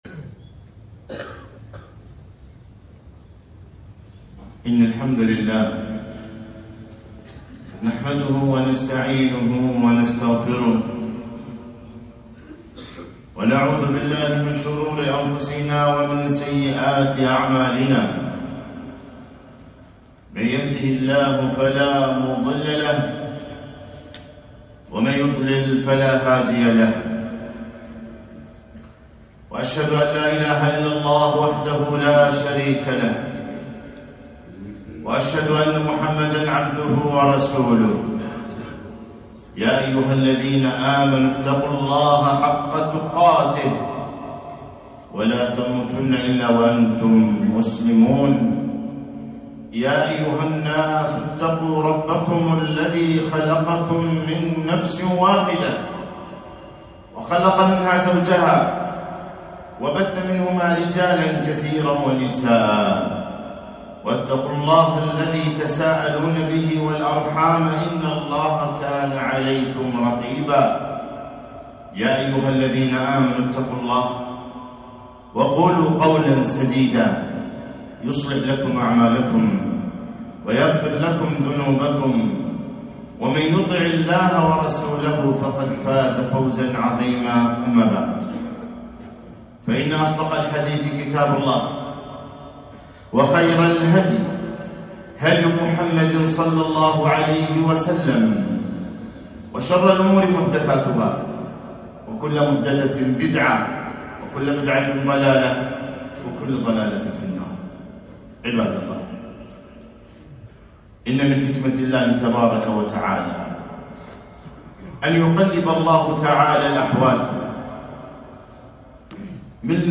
خطبة - الشتاء غنيمة العابدين